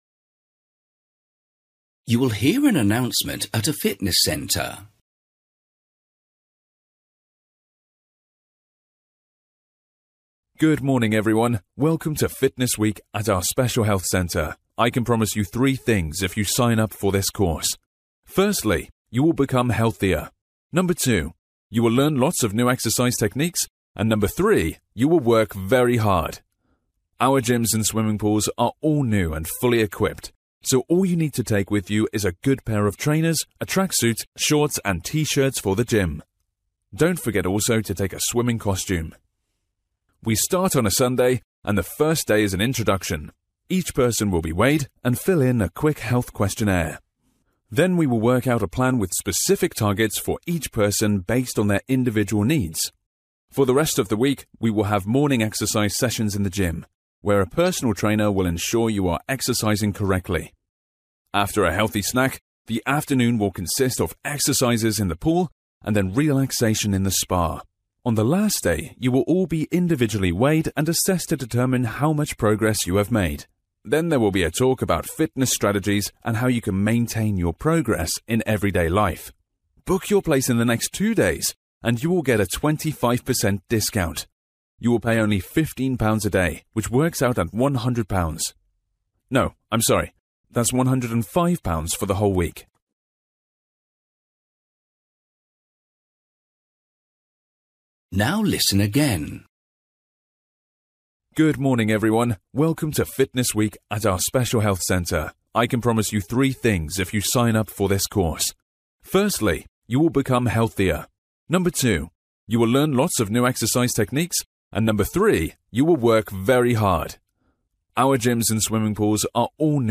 You will hear an announcement at a fitness centre.